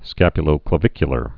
(skăpyə-lō-klə-vĭkyə-lər)